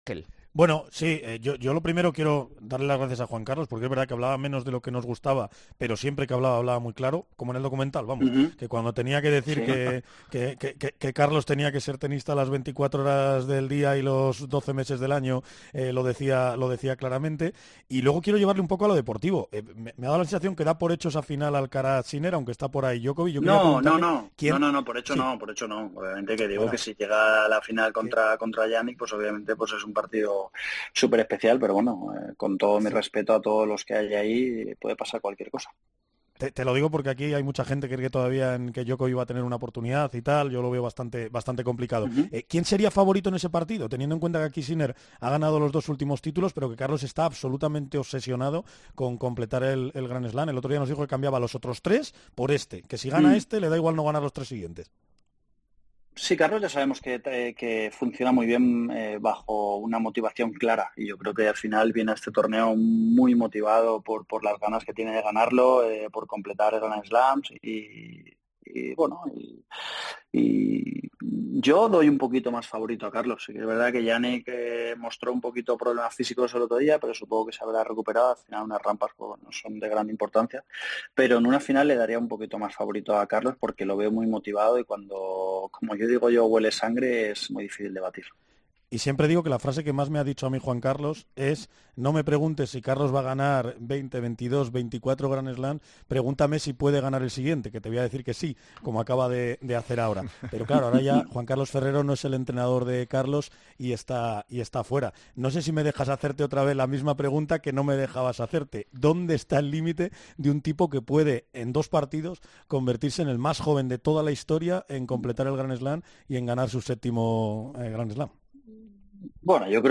El técnico valenciano ha explicado que "Carlos ya sabemos que que funciona muy bien bajo una motivación clara". A pesar de que Sinner ha ganado los dos últimos títulos, Ferrero da "un poquito más favorito a Carlos" para el torneo, ya que lo ve "muy motivado", ha dicho este martes en la entrevista de Juanma Castaño en El Partidazo de COPE.